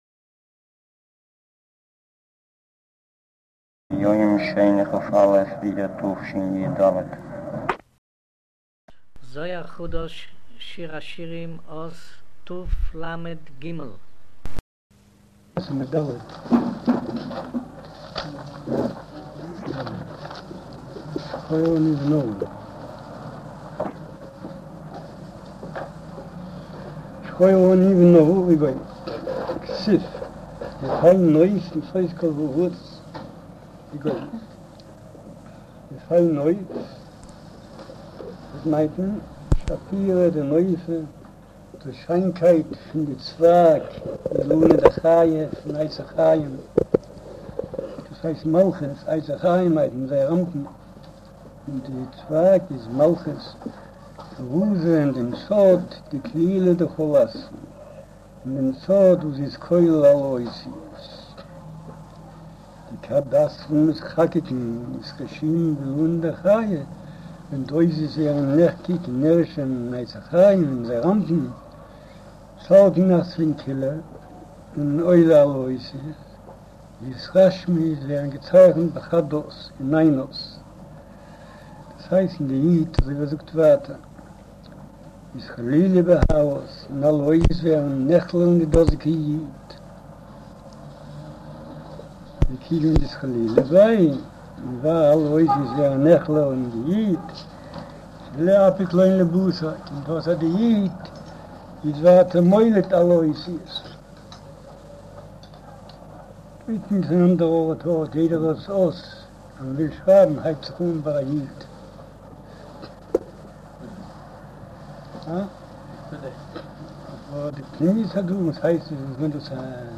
אודיו - שיעור